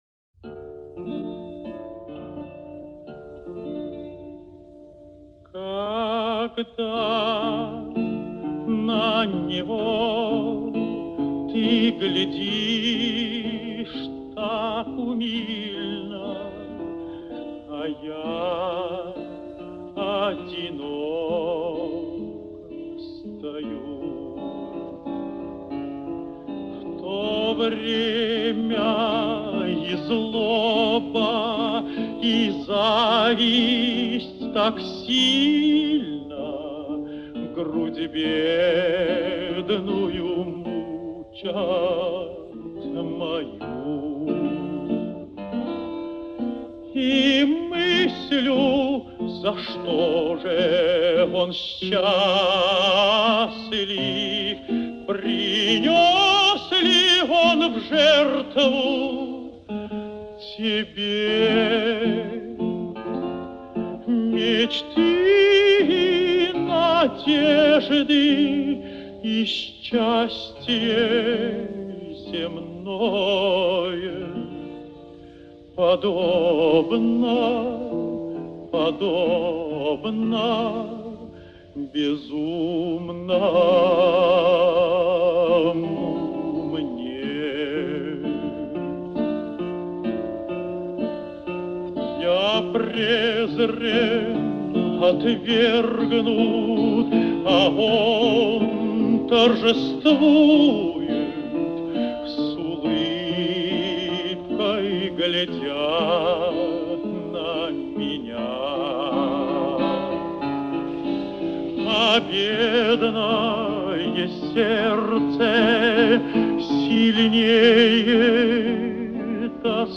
старинный русский романс